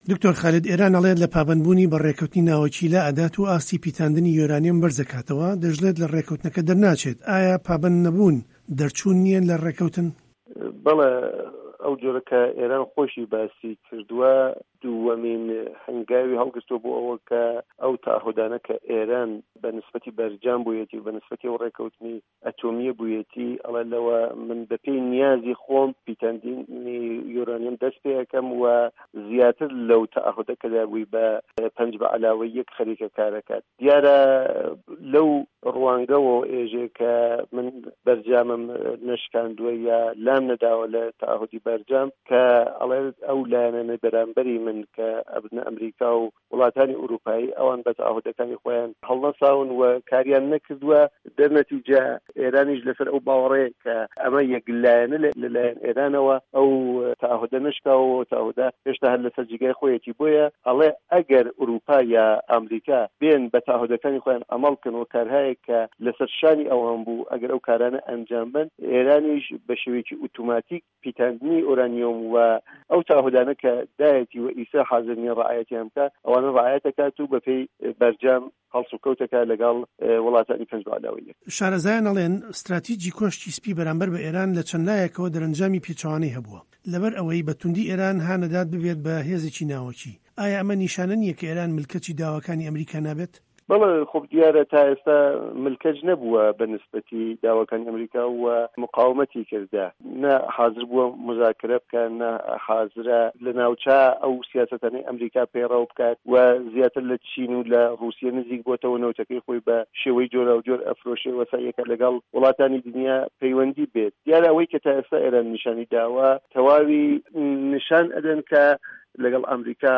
ڕۆژهه‌ڵاتی ناوه‌ڕاست - گفتوگۆکان